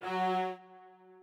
strings9_18.ogg